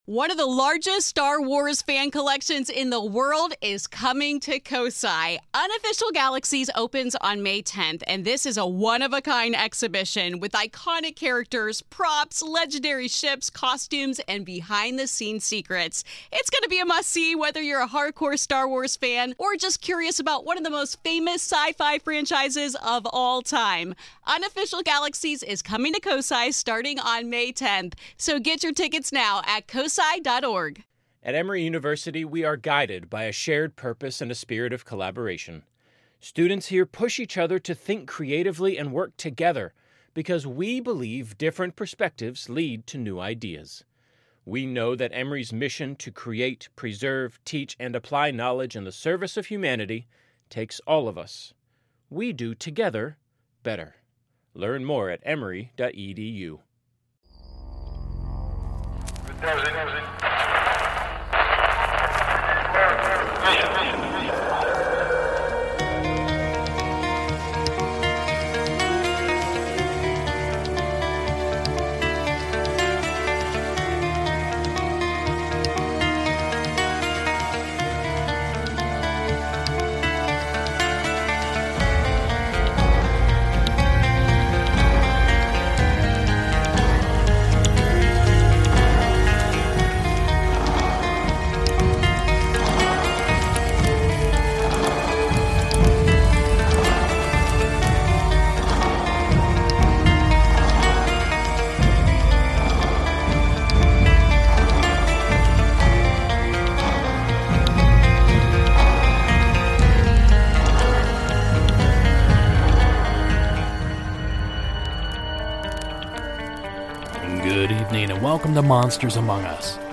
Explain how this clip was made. On this episode I play calls regarding a black cloaked entity wearing a pair of antlers, an Inuit legend come to life and the infamous Ohio grassman.